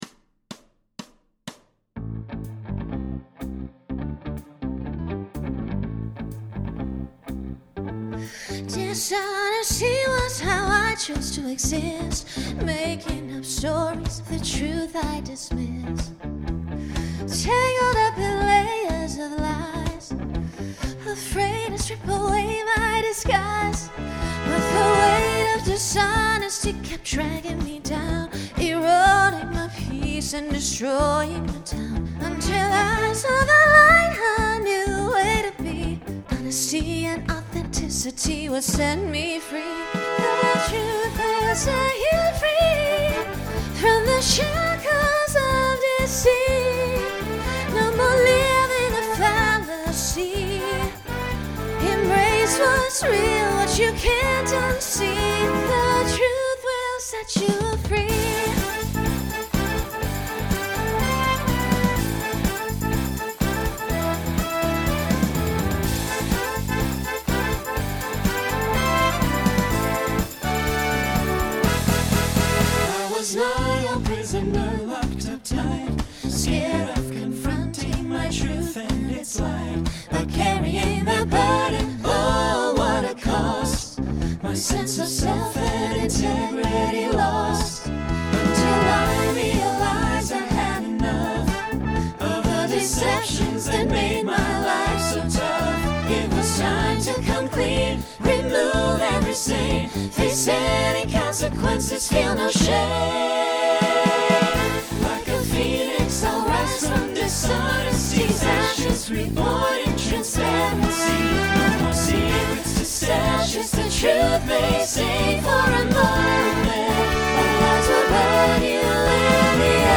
First chunk is a solo to facilitate a costume change.
Pop/Dance
Transition Voicing Mixed